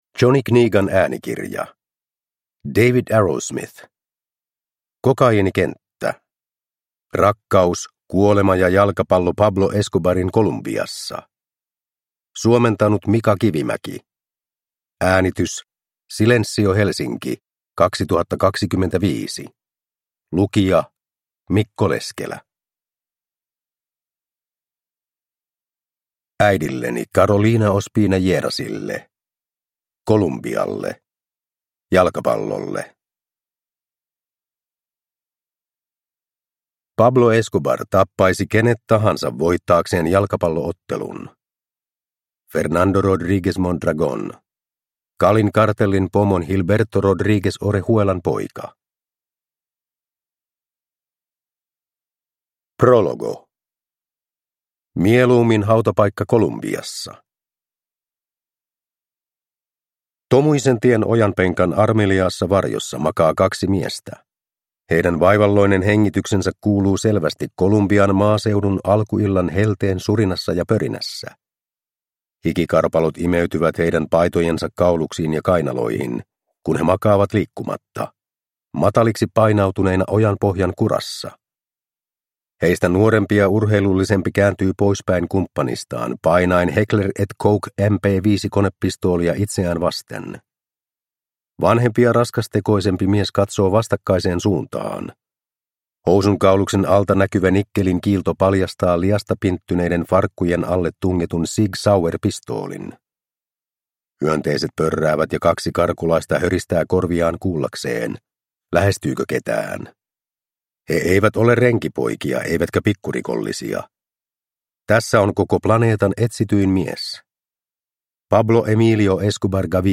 Kokaiinikenttä – Ljudbok